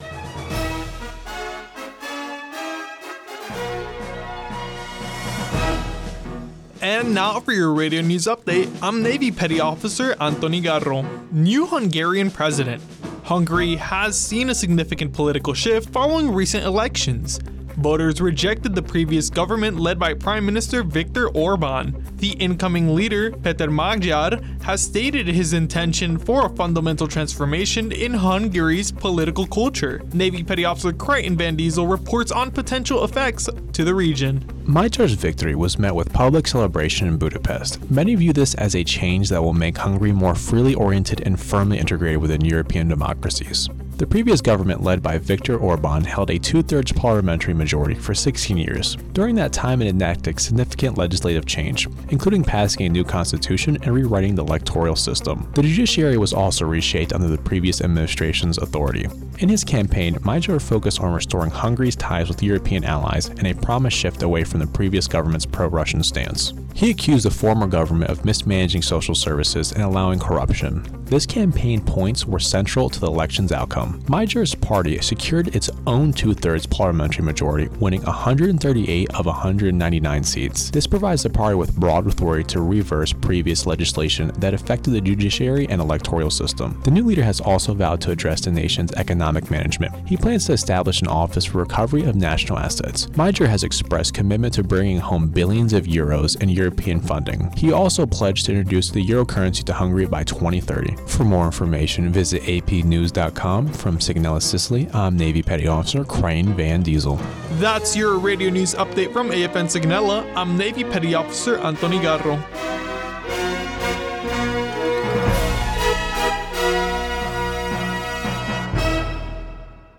Radio News 260414